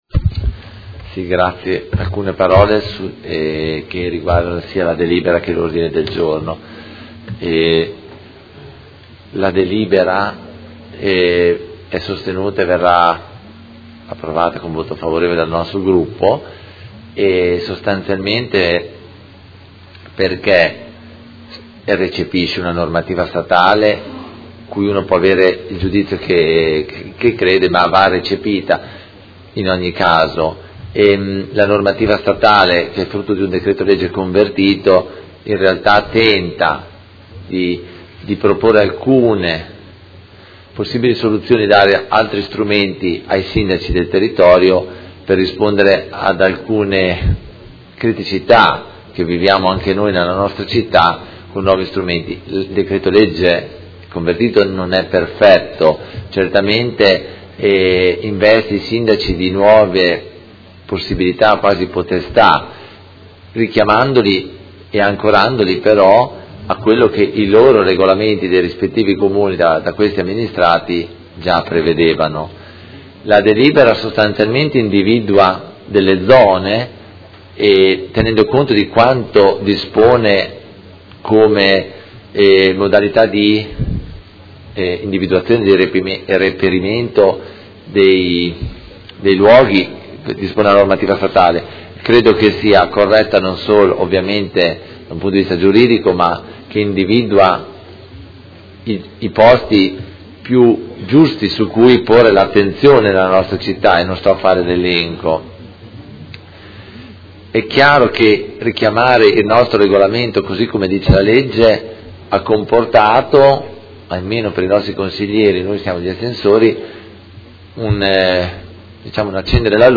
Seduta del 20/07/2017 Dibattito. Delibera: Modifiche al Regolamento di Polizia Urbana a seguito della L. 48/2017 – Approvazione e Odg n.11949: Regolamento Polizia Urbana